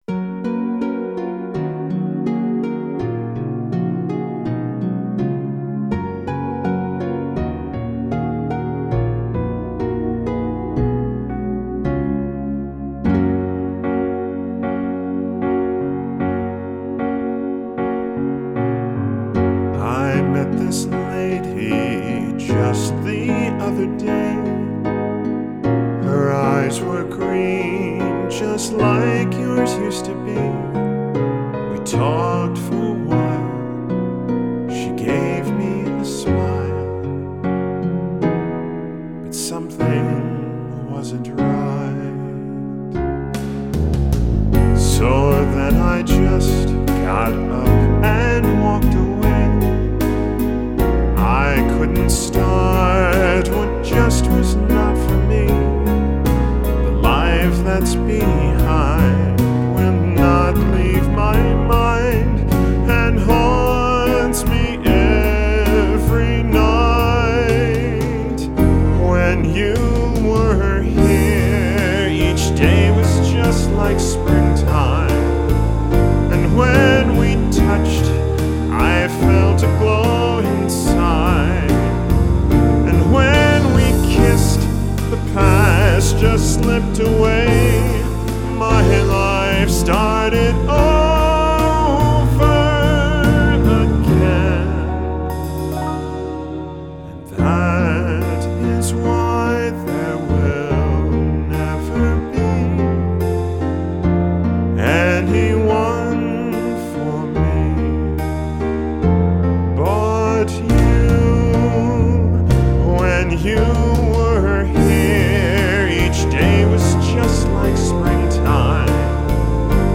vocals / guitars / sequencing